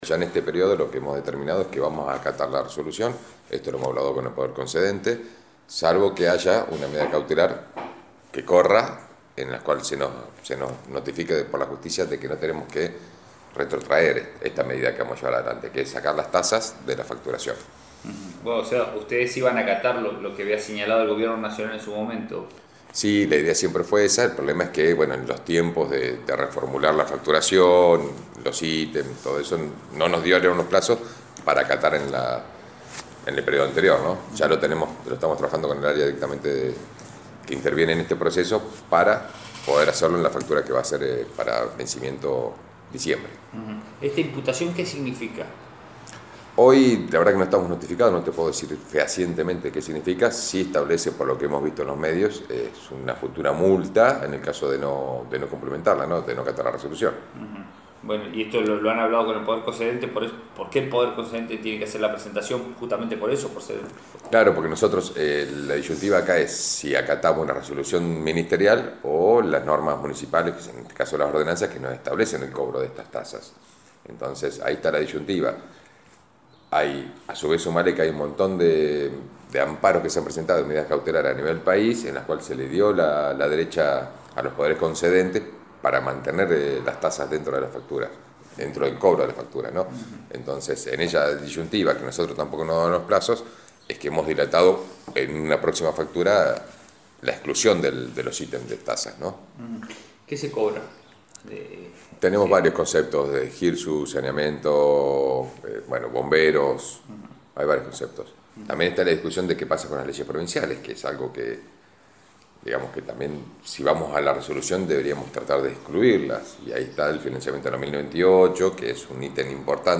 Aguardarán las acciones legales de la Municipalidad, coincidiendo en el diagnóstico respecto que la medida es atentatoria de las autonomías provinciales y municipales. Así se lo detalló al móvil de FM Bahía Engaño.